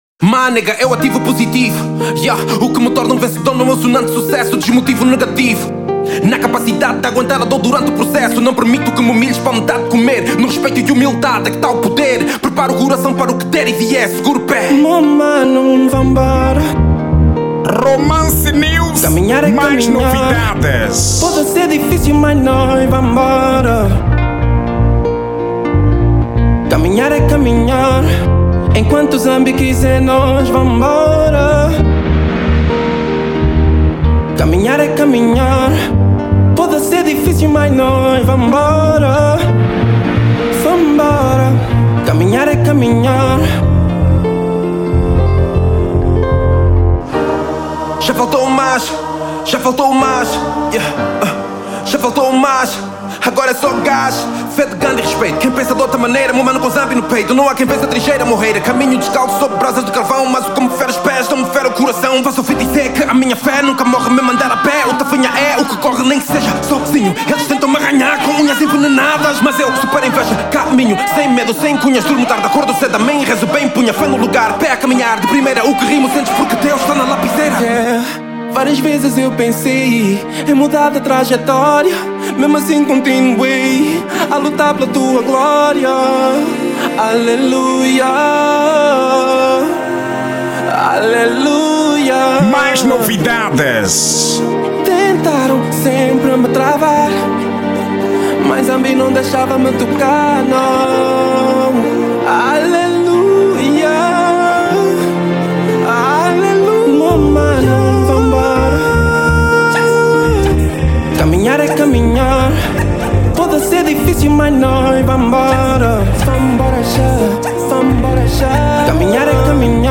Estilo: Rap